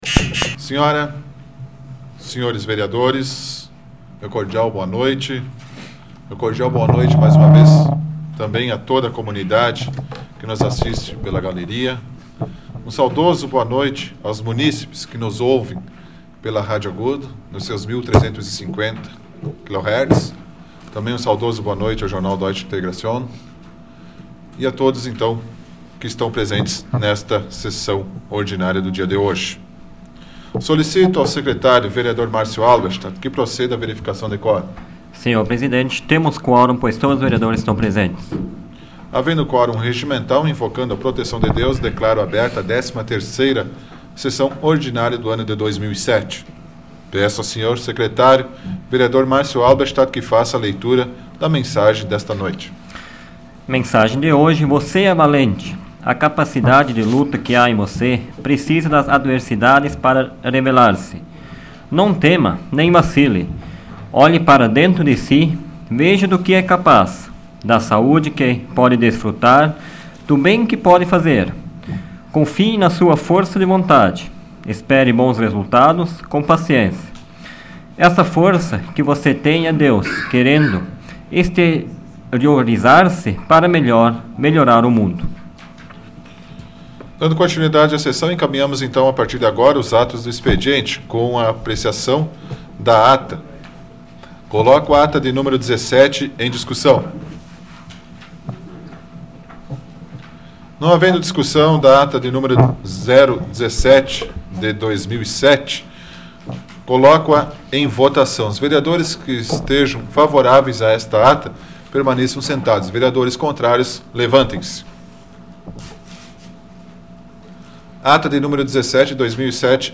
Áudio da 87ª Sessão Plenária Ordinária da 12ª Legislatura, de 28 de maio de 2007